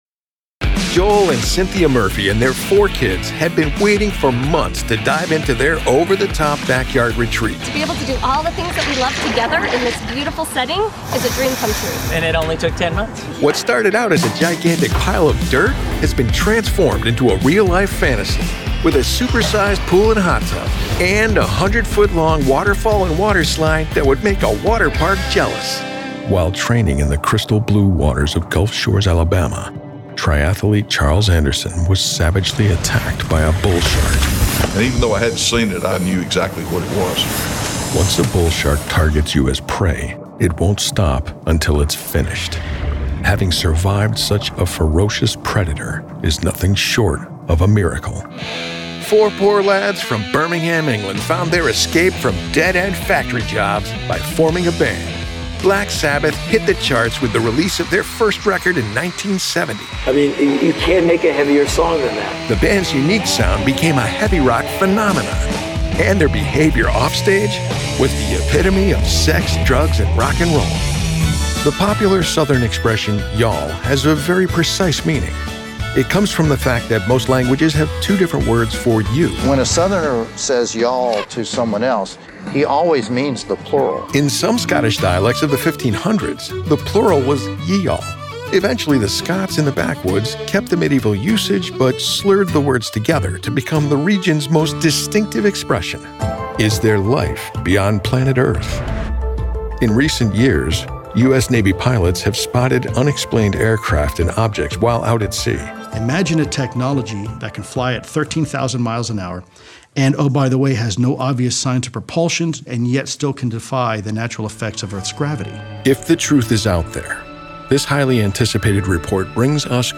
Adult, Mature Adult
Has Own Studio
standard us | natural
cool
smooth/sophisticated
warm/friendly
husky